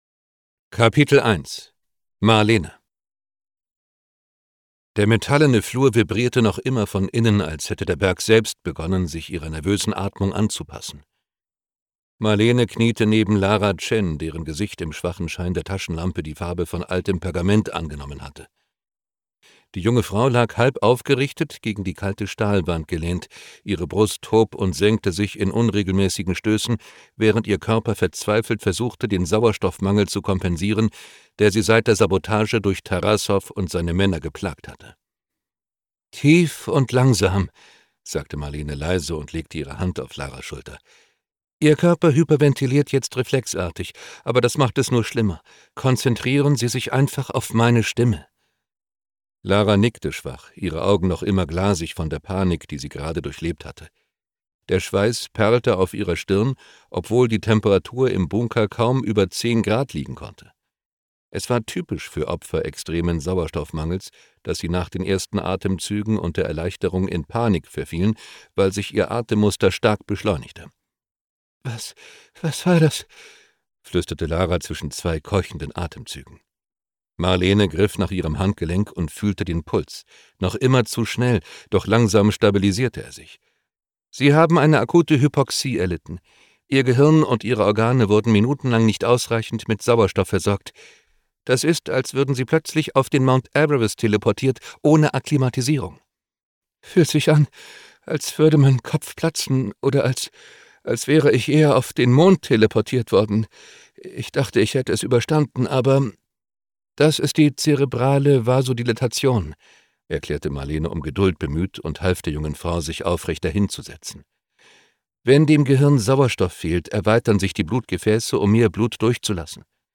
Science Fiction Thriller
Gekürzt Autorisierte, d.h. von Autor:innen und / oder Verlagen freigegebene, bearbeitete Fassung.